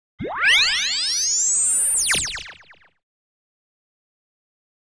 Синтетический вариант